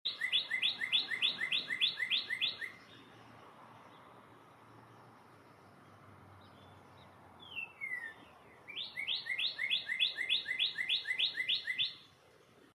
Northern Cardinal bird sounds on sound effects free download
Northern Cardinal bird sounds on a spring morning